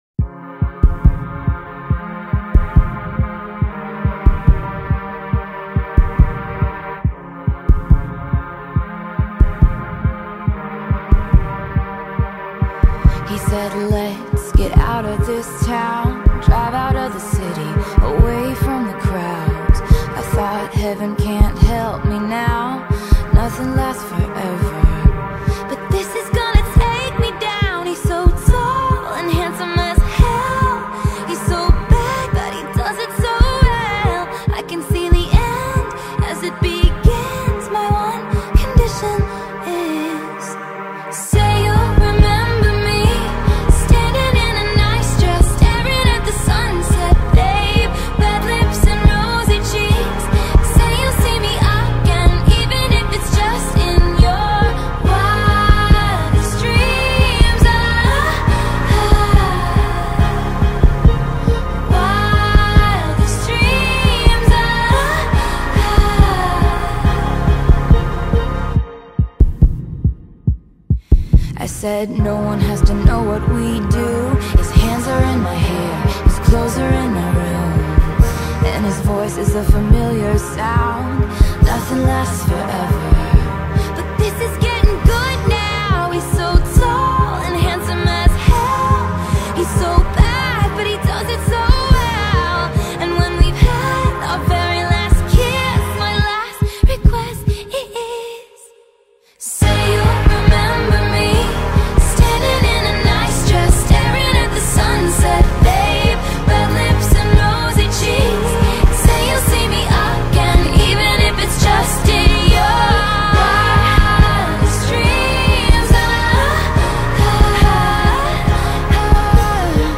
ژانر: پاپ / راک